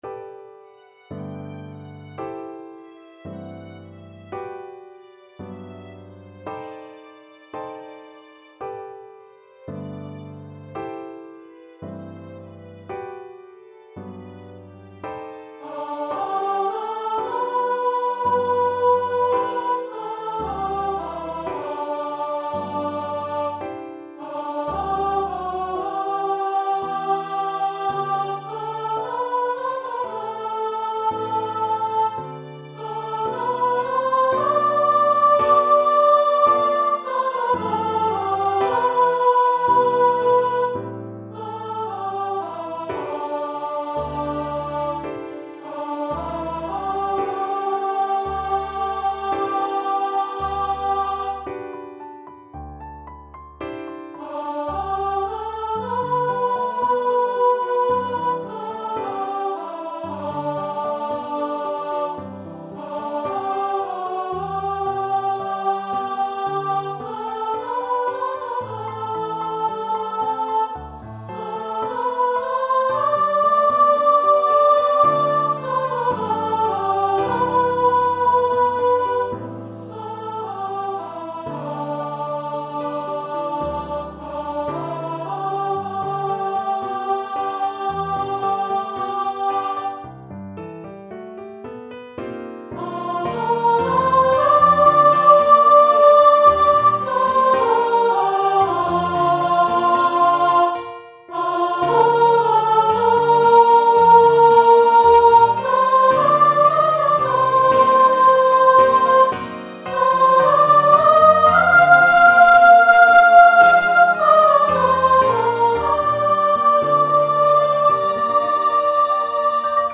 Voice 1